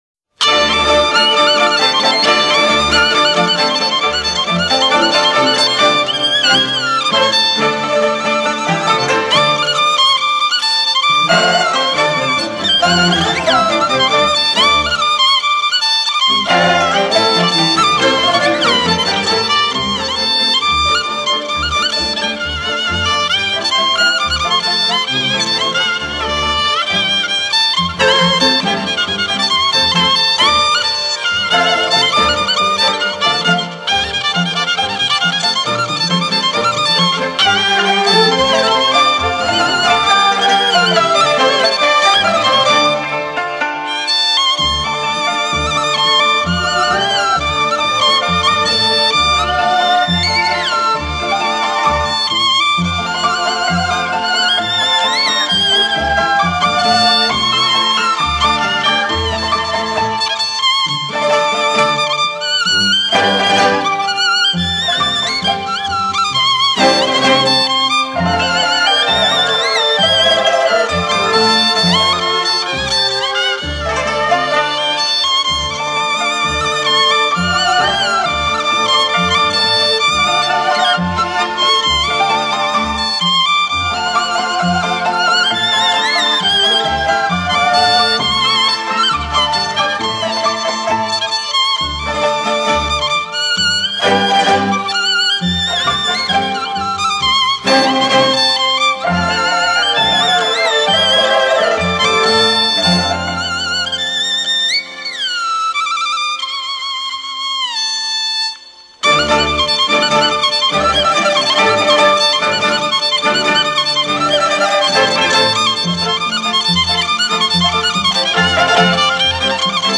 这两种乐器都以爽健、开朗取胜，但有时又不失细腻之处。本辑所收的板胡曲民间风格强烈，乡土气息浓郁，充满着生活的情趣。
高音板胡独奏